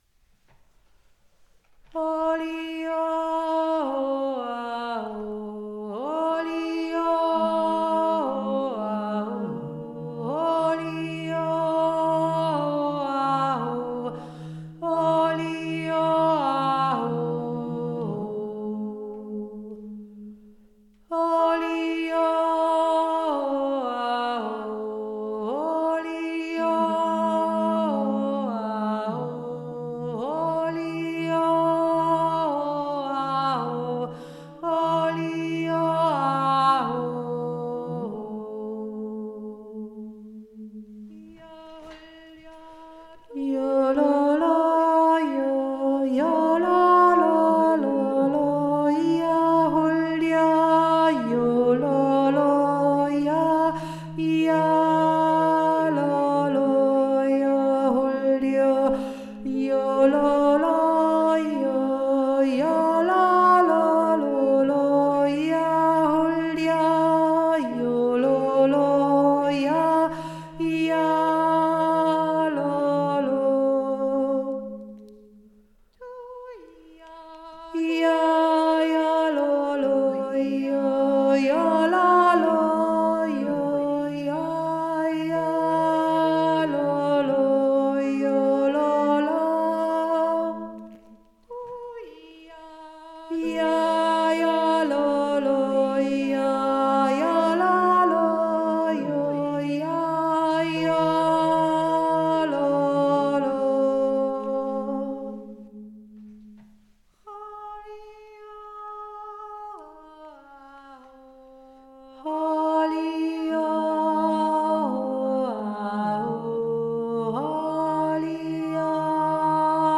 tiefere Stimme
z-umn-sch-tiefere-stimme.mp3